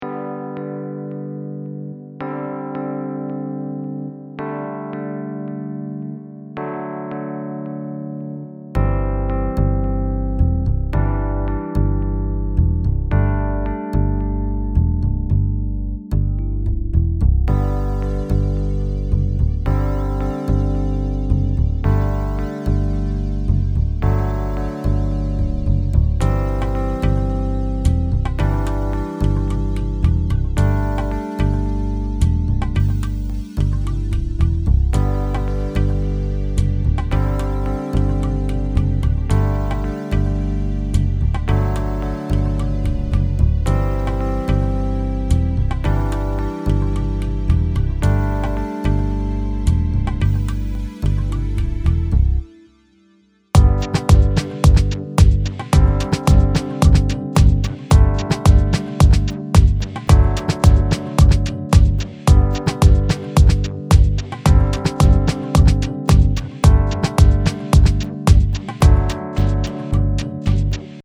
That's why I started Apple Garageband and recorded some chords as well as a nice bassline. Nothing special, just 8 Chords, 2 Basslines and the familiar Garageband Sound-Quality (All these Instruments come right out of Logic Audio).